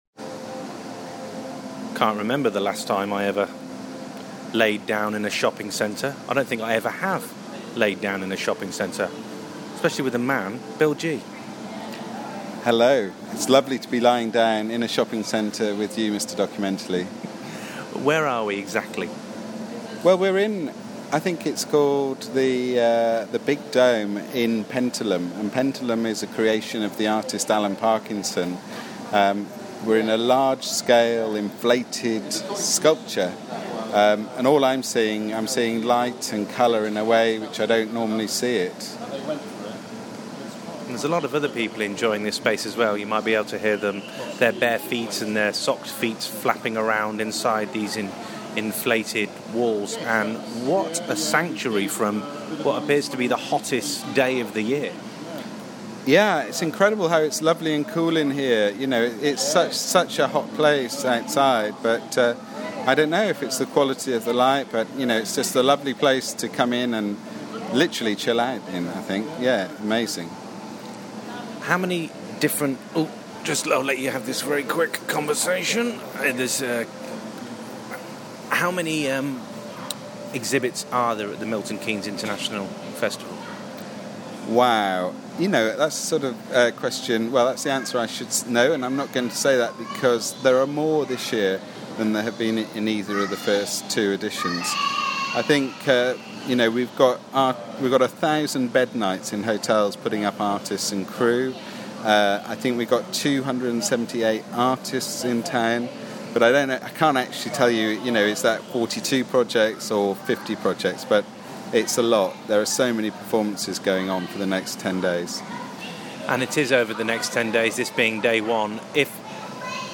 in a shopping centre